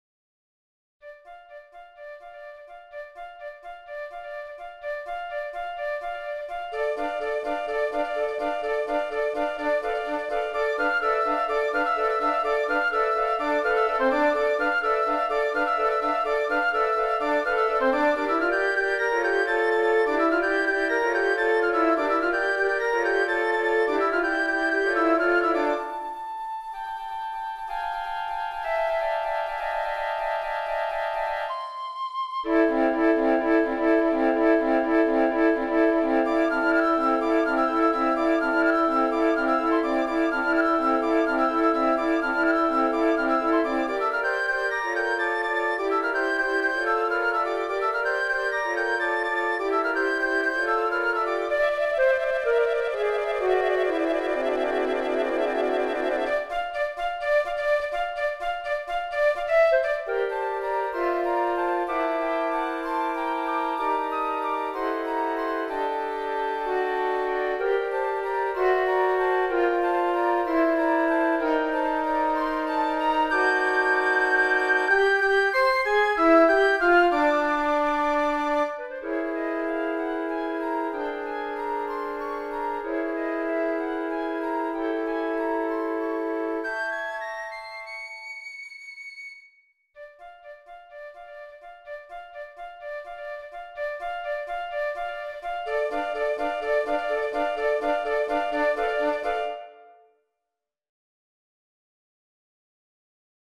Voicing: Flute Choir